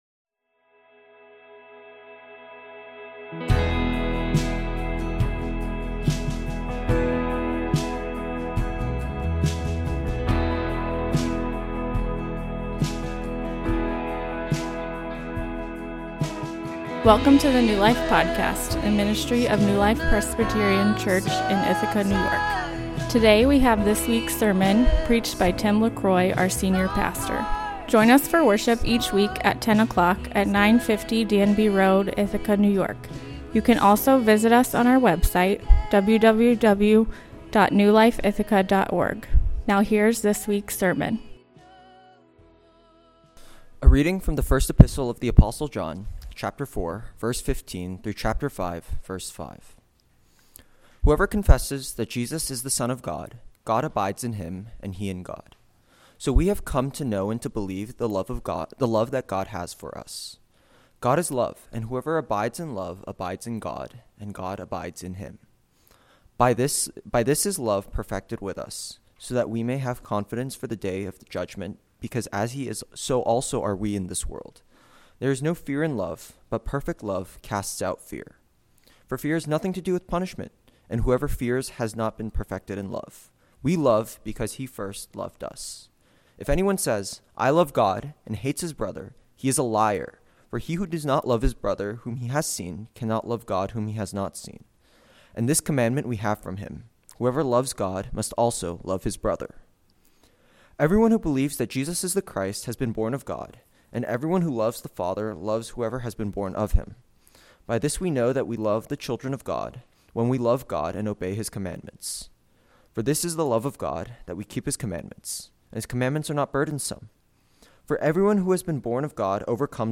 A sermon on 1 John 4:15-5:5 Sermon Outline I. Not just knowledge, surrender to belief II.